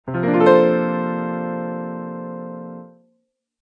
Sus4 or 7sus(4) Chords:
1,4,7 9,4,13,1 (example in midi and